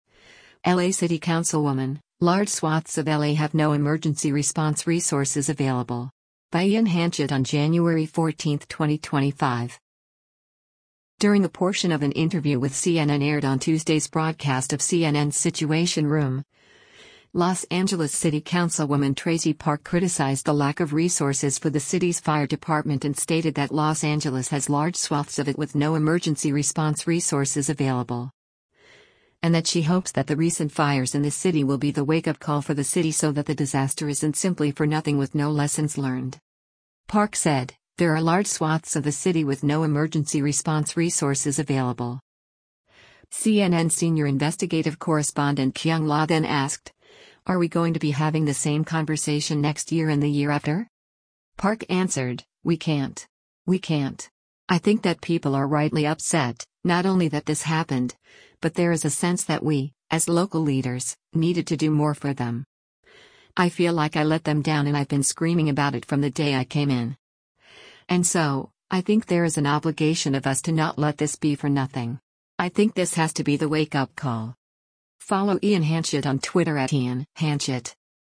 During a portion of an interview with CNN aired on Tuesday’s broadcast of CNN’s “Situation Room,” Los Angeles City Councilwoman Traci Park criticized the lack of resources for the city’s fire department and stated that Los Angeles has “large swaths” of it “with no emergency response resources available.”
CNN Senior Investigative Correspondent Kyung Lah then asked, “Are we going to be having the same conversation next year and the year after?”